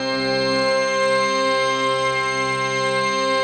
PAD 46-4.wav